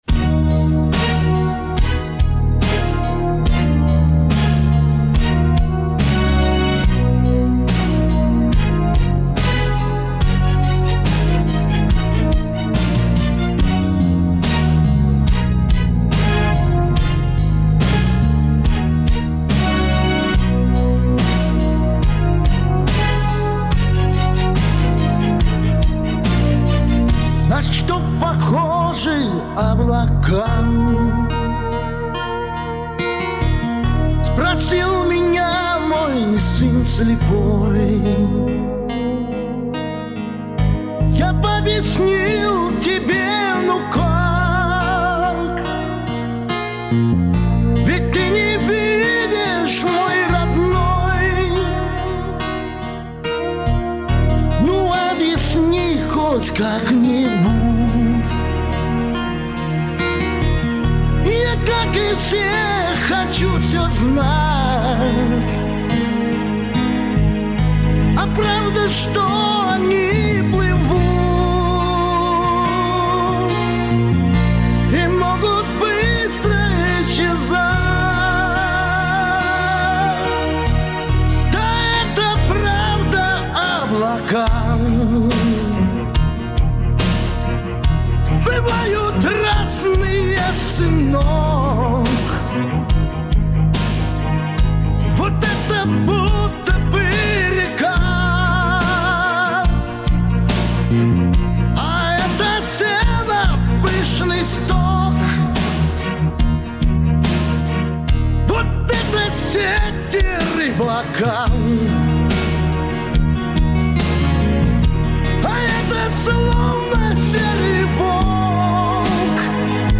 а там просто караоке и далеко не лучшее